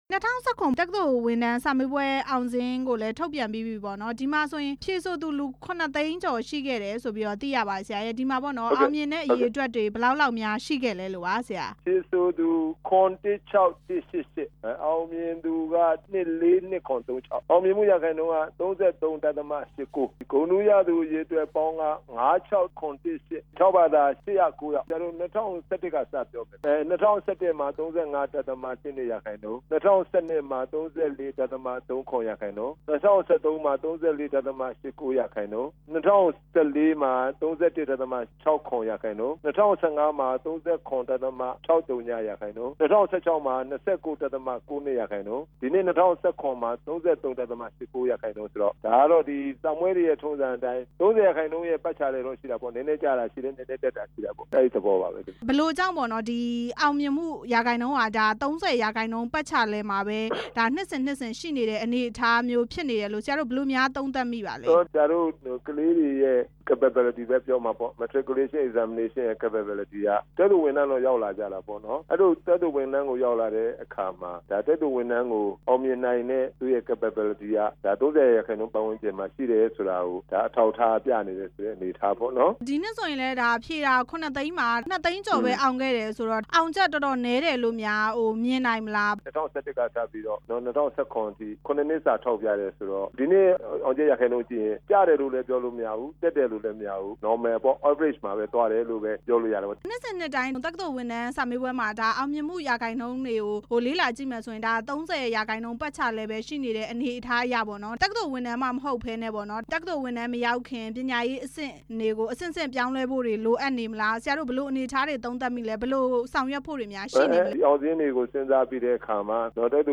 ဆယ်တန်းအောင်ချက် ဒေါက်တာခိုင်မြဲ ကို မေးမြန်းချက်